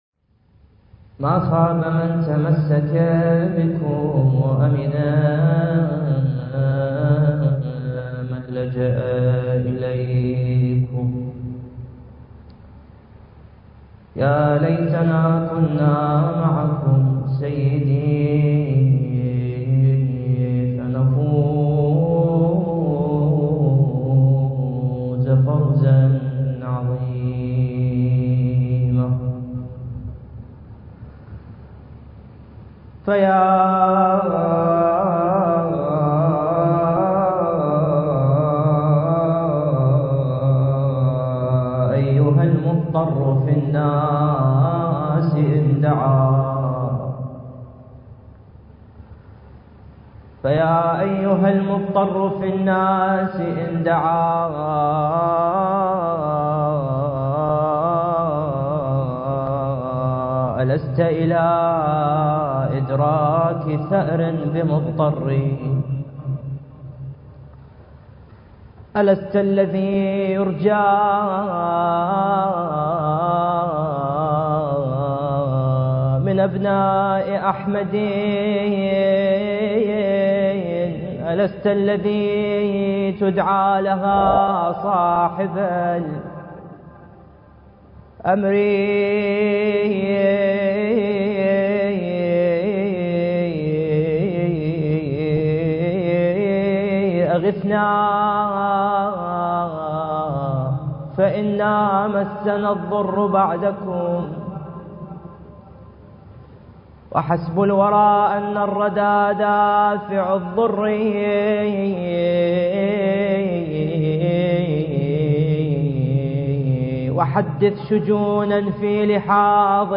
المكان: الحسينية المهدية - مركز الدراسات التخصصية في الإمام المهدي (عجّل الله فرجه) - النجف الأشرف